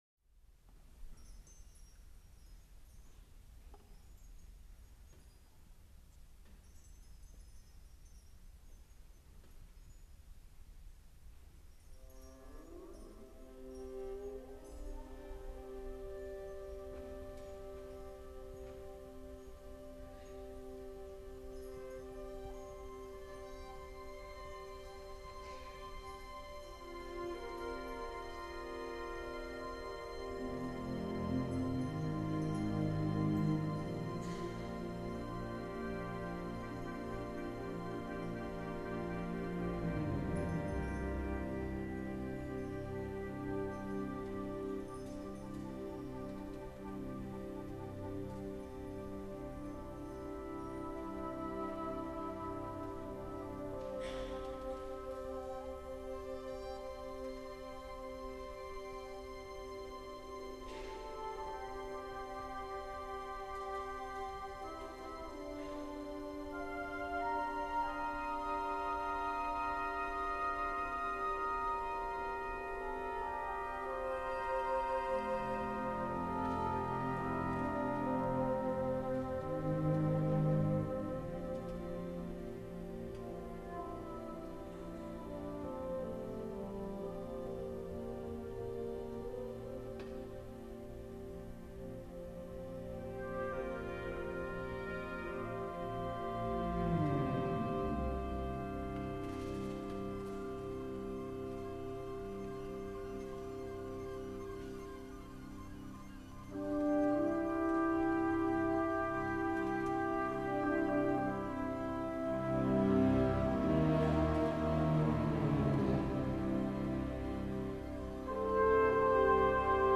for Chamber Orchestra (1987)